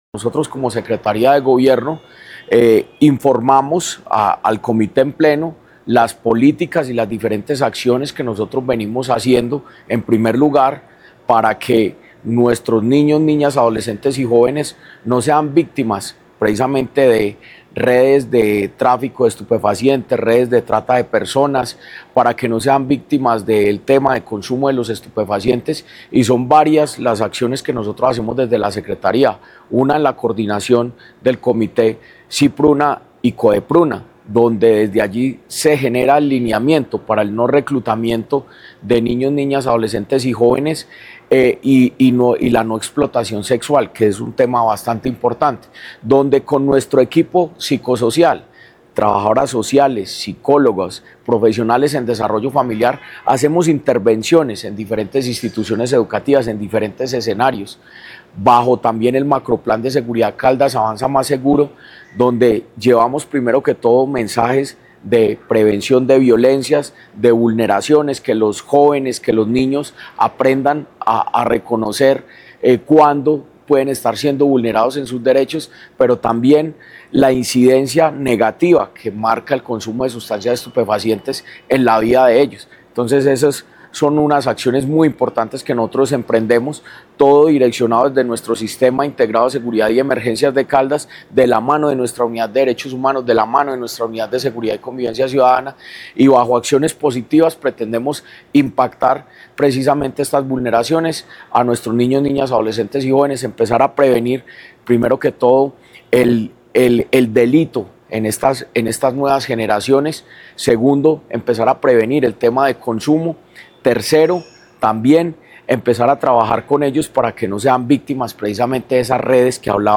Jorge Andrés Escudero, secretario de Gobierno de Caldas.
Jorge-Andres-Gomez-Escudero-secretario-de-Gobierno-de-Caldas-Comite-Politica-Social.mp3